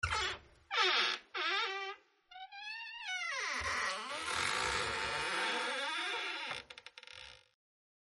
Creaking Door Open Sound Effect Free Download
Creaking Door Open